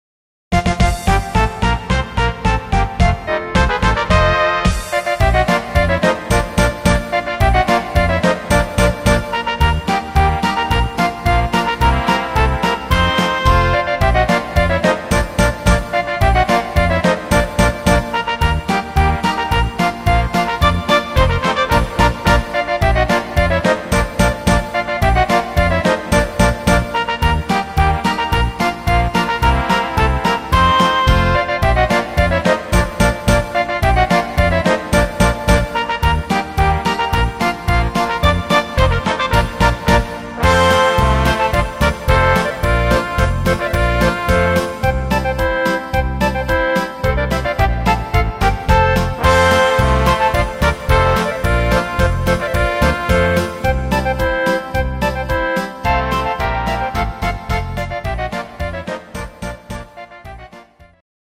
Rhythmus  Polka
Art  Volkstümlich, Deutsch